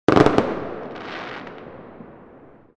Firework Burst Sound Effect
Description: Firework burst sound effect. Firework explosion boom followed by sparkling or crackling sounds.
Firework-burst-sound-effect.mp3